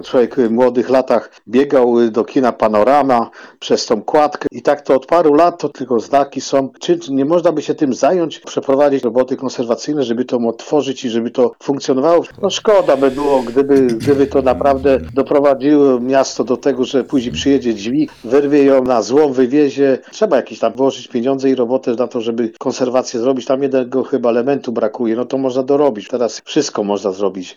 W tej sprawie do Twojego Radia zatelefonował słuchacz:.